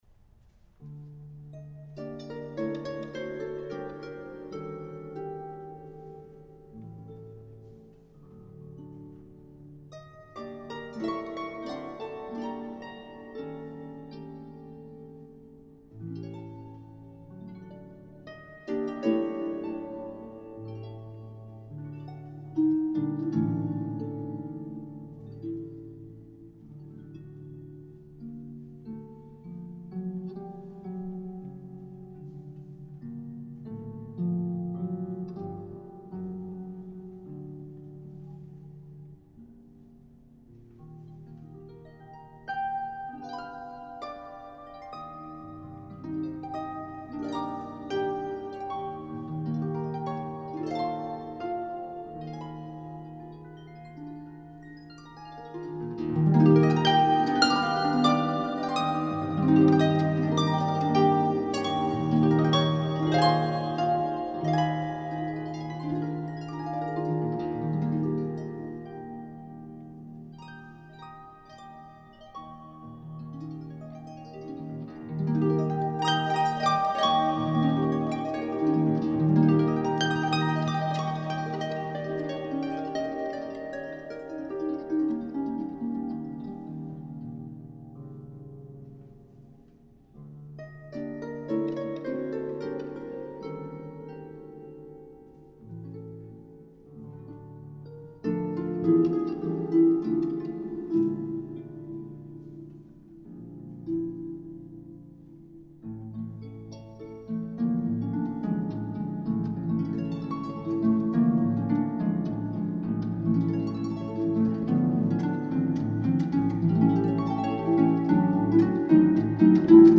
Harfe & Orgel
Eugen Onegin-Fantasie für Harfe Solo